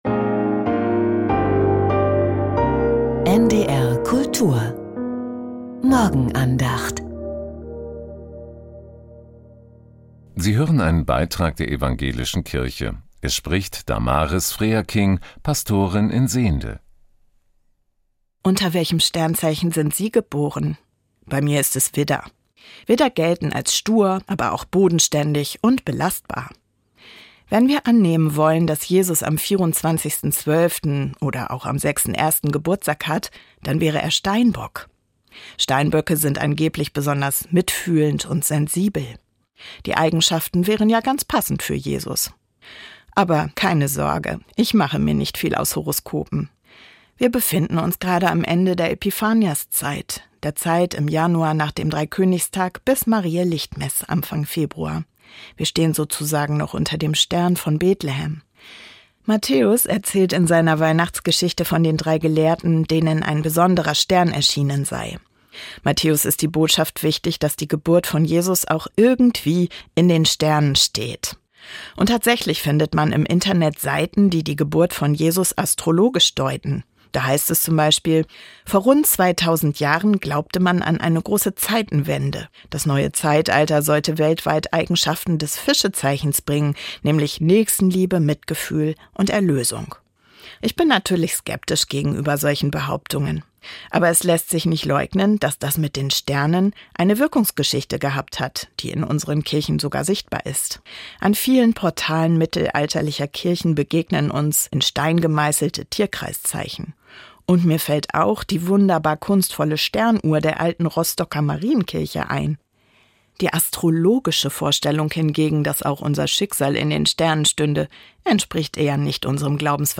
Steinbock ~ Die Morgenandacht bei NDR Kultur Podcast